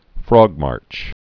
(frôgmärch, frŏg-)